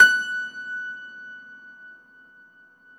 53a-pno20-F4.wav